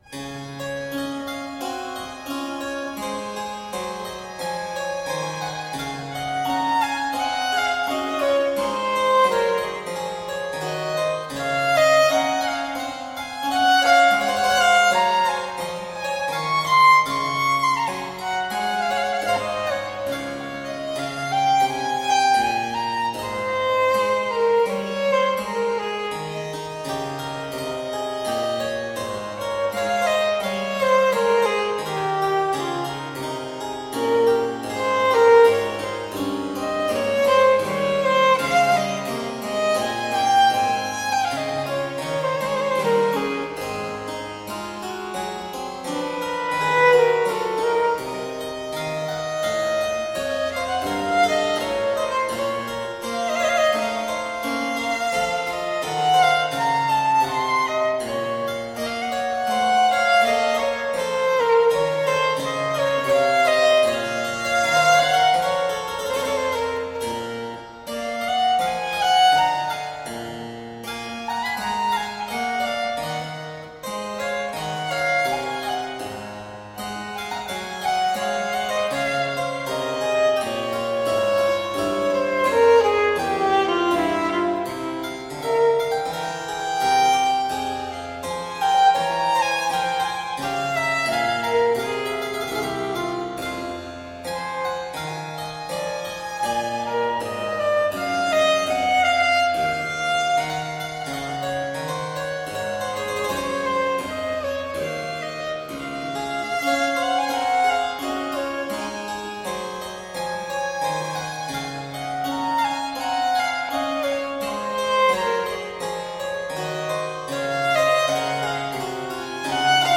Baroque violin & harpsichord.